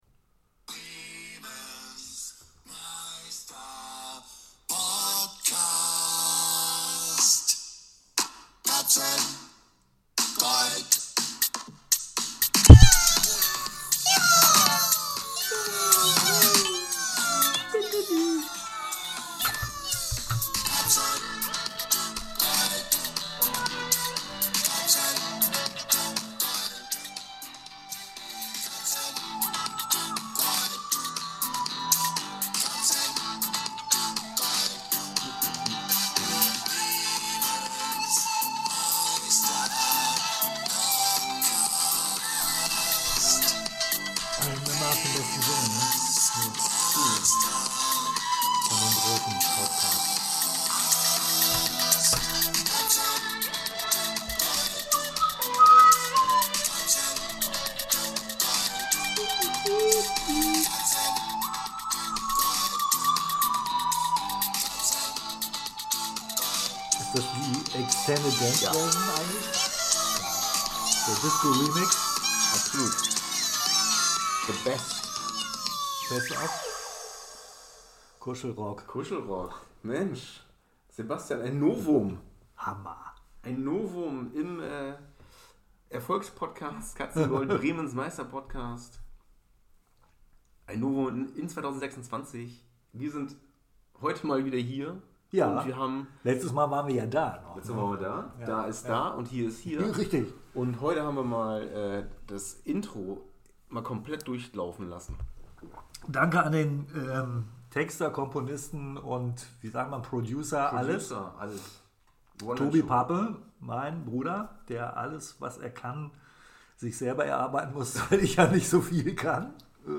Als erstes Highlight gibt es das bekannte Intro erstmalig in voller Länge.